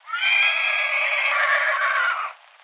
horse.wav